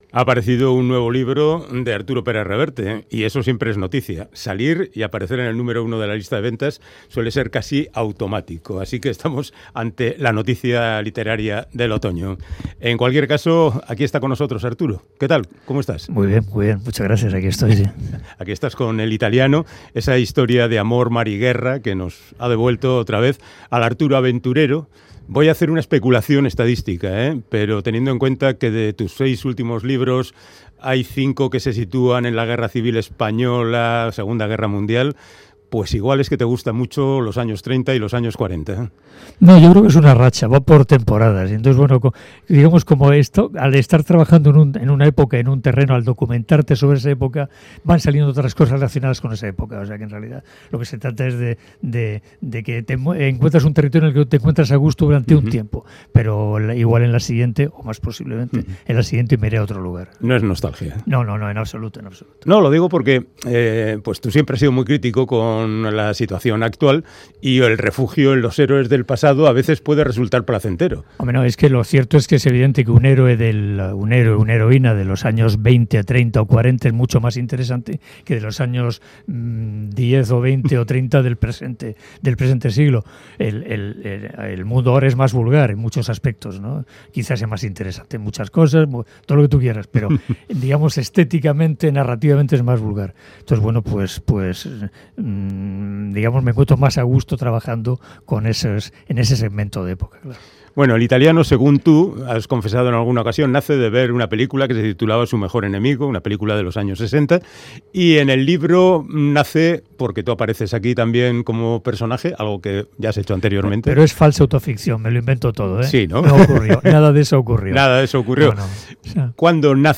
ENTREVISTAS APARECIDAS EN T.V. y RADIO sobre " EL ITALIANO "